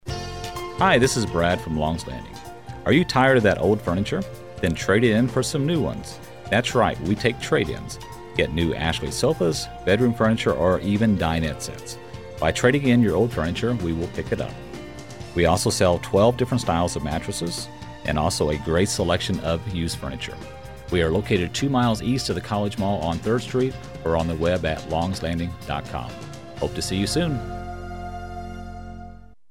5-18-09 with music.mp3